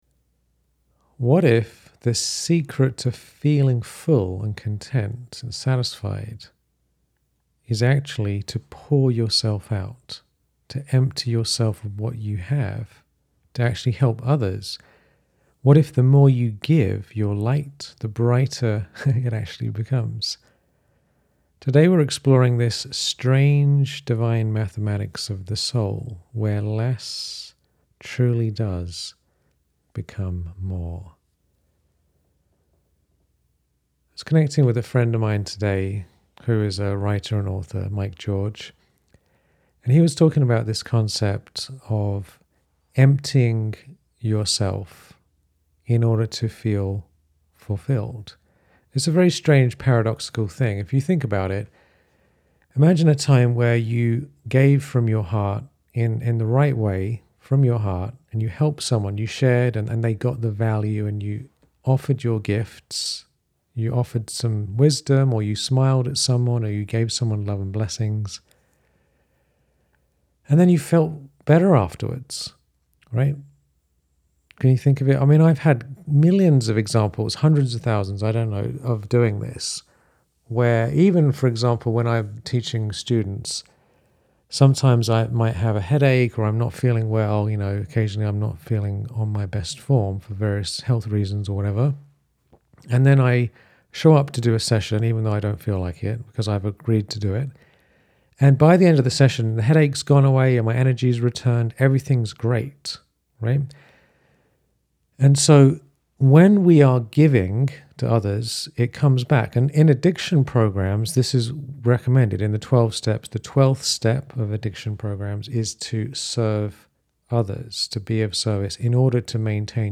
In this heart-opening episode, we explore the divine mathematics of fulfillment — how sharing your love, wisdom, and blessings actually fills you with more. Through stories, reflection, and a short guided sakash meditation, you’ll rediscover the joy of being a channel for light and goodness.